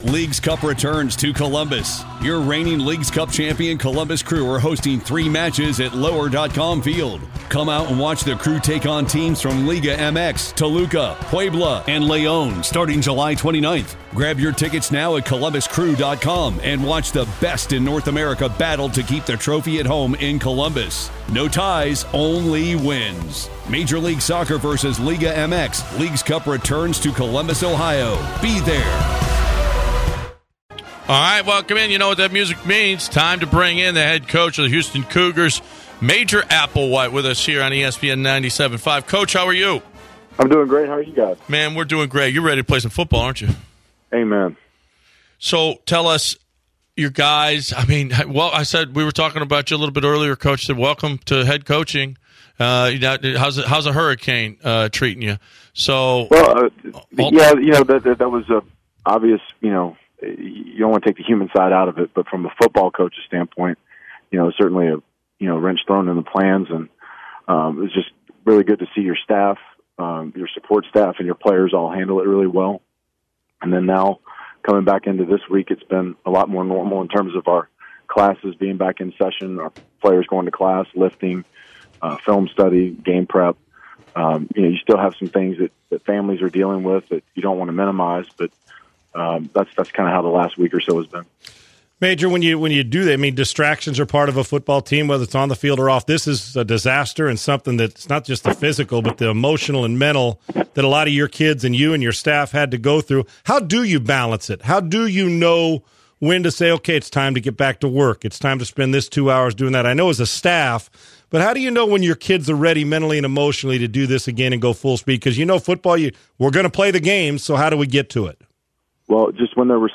University of Houston head football coach speaks